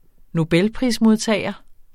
Udtale [ noˈbεlˌpʁismoðˌtæːjʌ ]